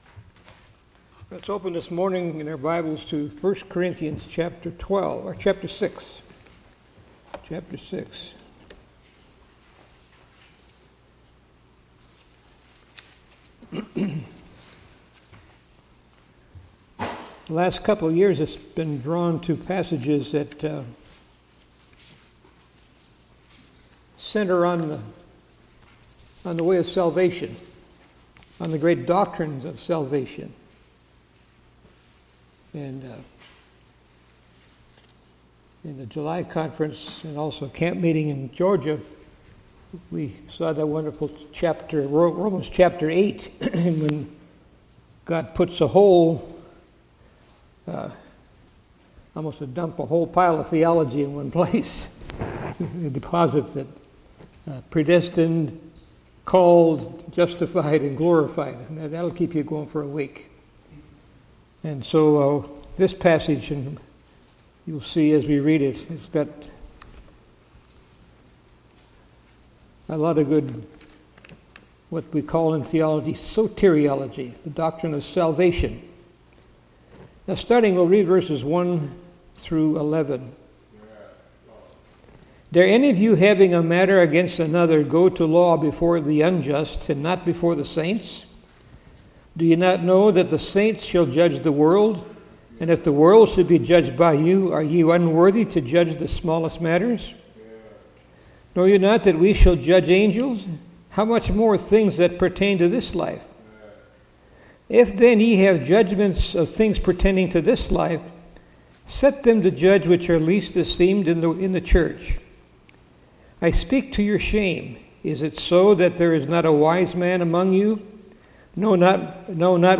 Series: 2019 August Conference
Session: Morning Session